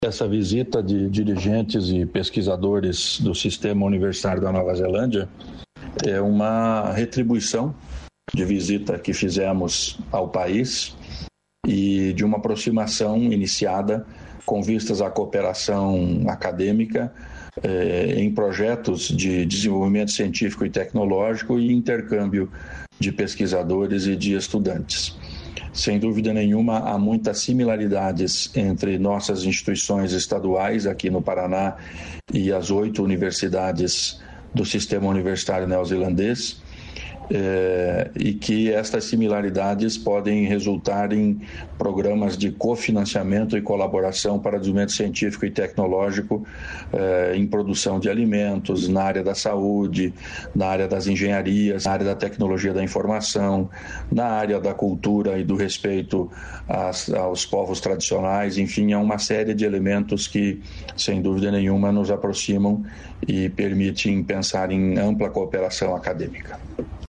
Sonora do secretário estadual da Ciência, Tecnologia e Ensino Superior do Paraná, Aldo Nelson Bona, sobre a Missão na Nova Zelândia